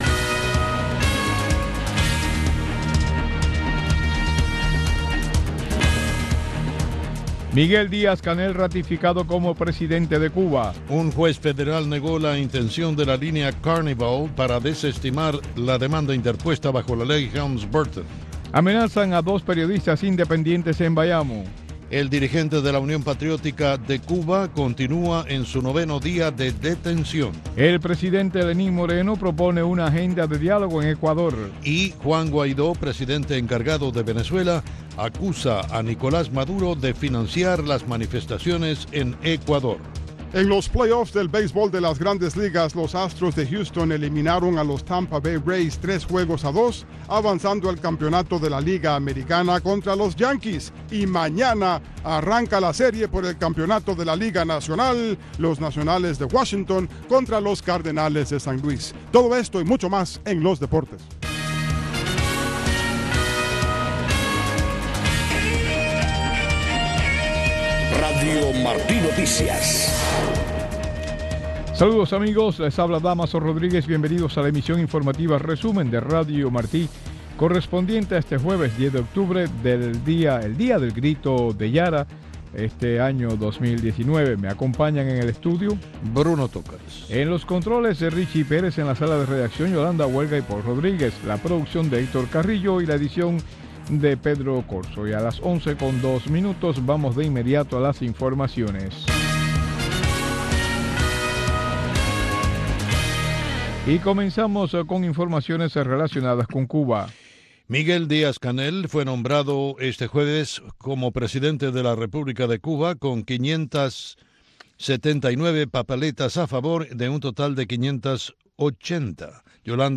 Noticiero de Radio Martí 11:00 PM